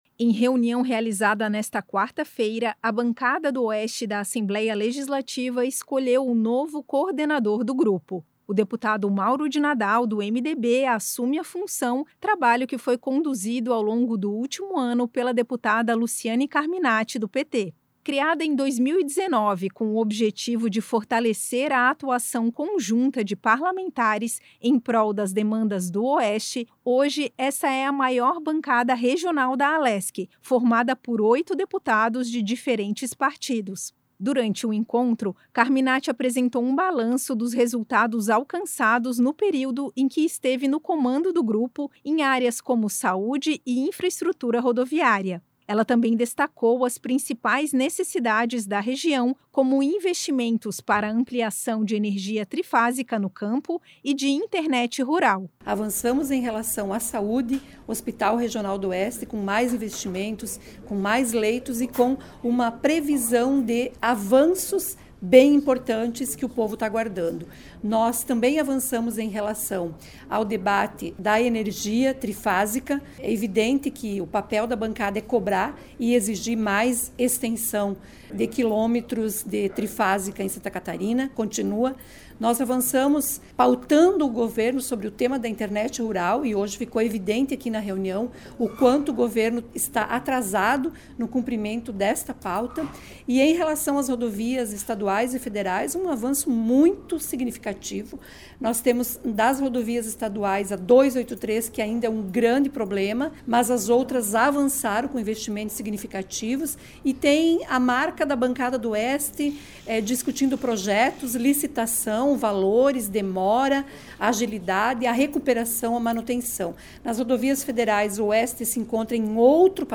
Entrevistas com:
- deputada Luciane Carminatti (PT),
- deputado Mauro de Nadal (MDB).